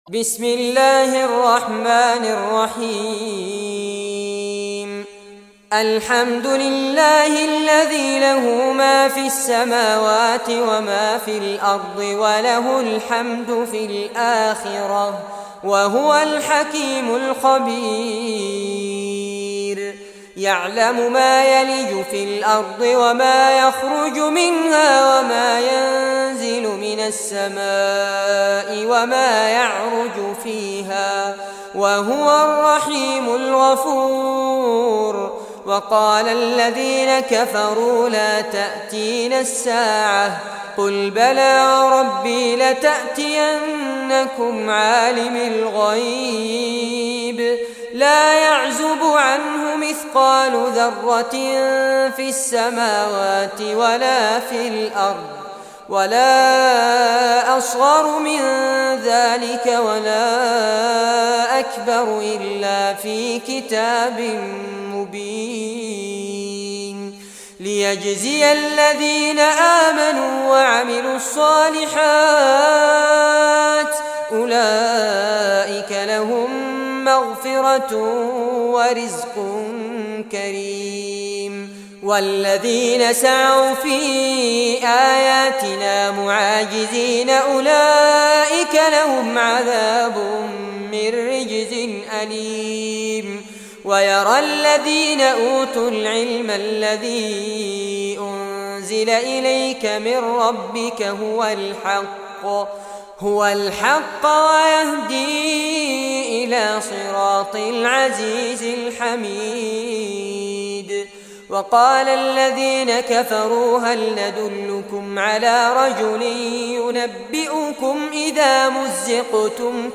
Surah Saba Recitation by Fares Abbad
Surah Saba, listen or play online mp3 tilawat / recitation in arabic in the beautiful voice of Sheikh Fares Abbad.